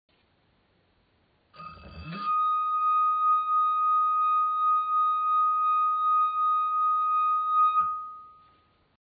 Als je met je natte vinger over de rand van het glas wrijft, dan hoor je een toon.
Als je met je vinger over de rand van het glas wrijft, dan gaat het glas met het water trillen. Dat trillen hoor je als het geluid.